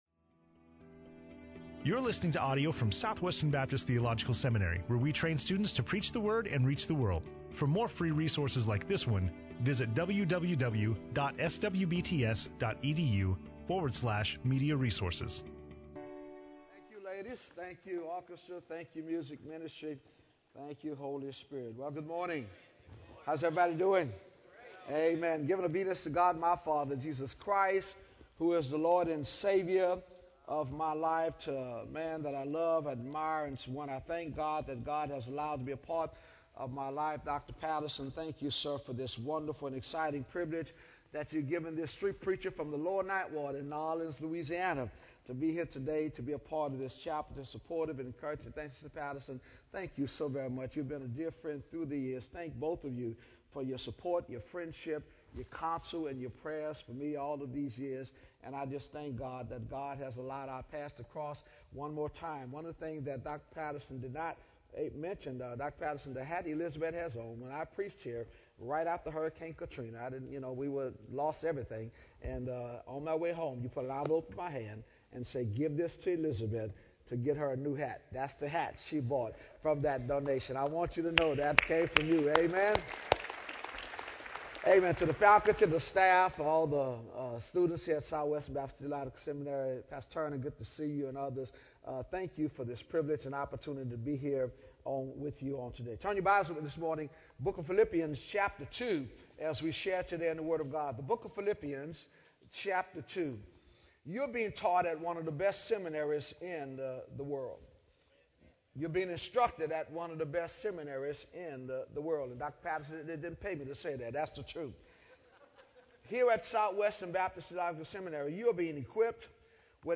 Dr. Fred Luter speaking on Philippians 2:5-8 in SWBTS Chapel on Wednesday March 28, 2012